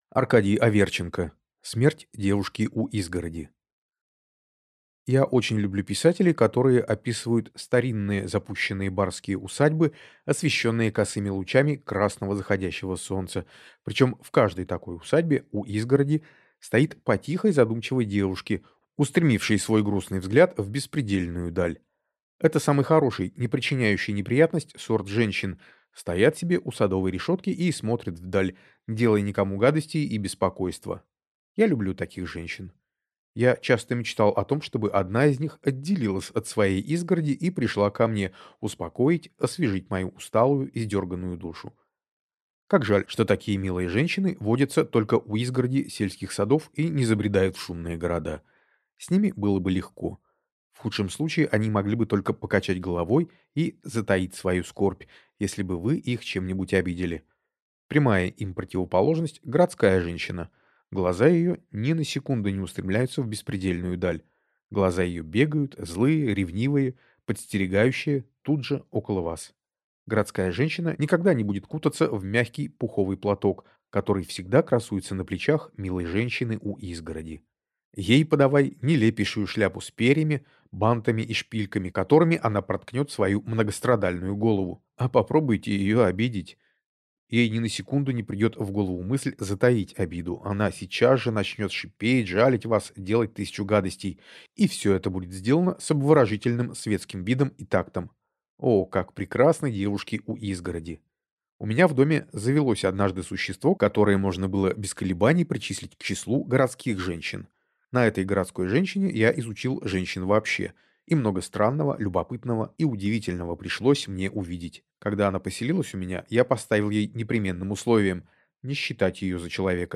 Аудиокнига Смерть девушки у изгороди | Библиотека аудиокниг